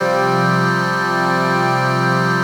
CHRDPAD078-LR.wav